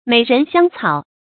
美人香草 注音： ㄇㄟˇ ㄖㄣˊ ㄒㄧㄤ ㄘㄠˇ 讀音讀法： 意思解釋： 舊時詩文中用以象征忠君愛國的思想。